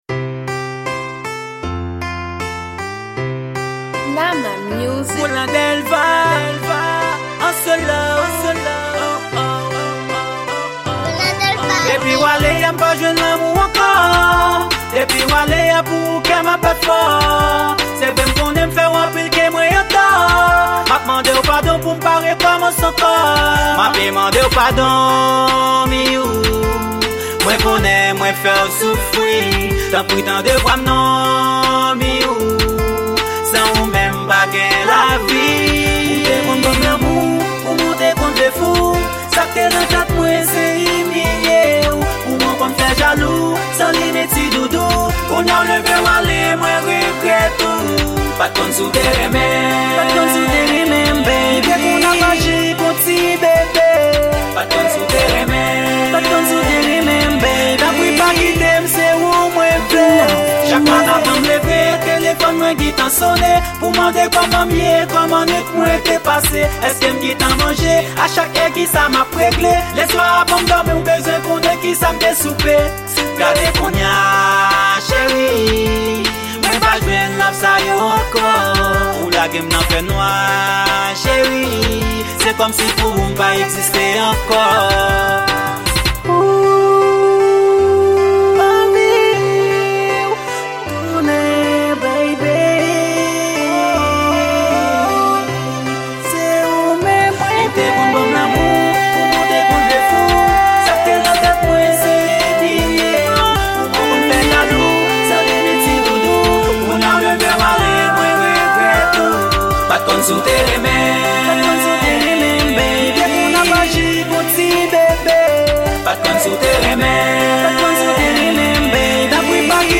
Genre: RnB